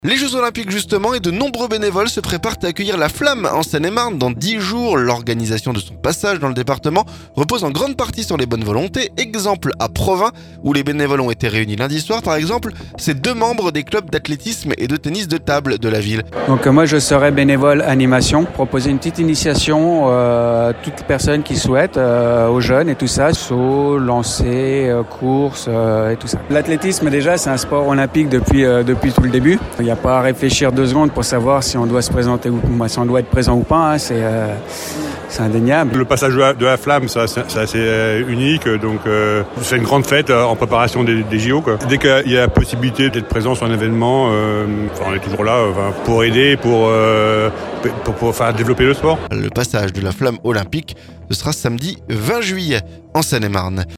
Exemple à Provins, où les bénévoles ont été réunis lundi soir. Par exemple ces deux membres des clubs d'athlétisme et de tennis de table de la ville.